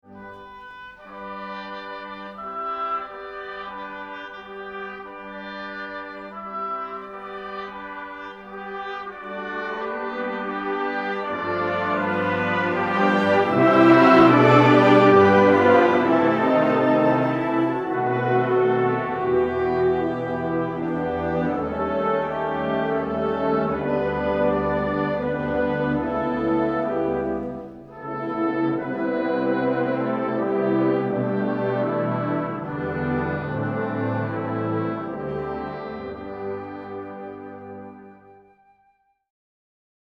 This is a major work for Concert Band or Symphonic Wind Band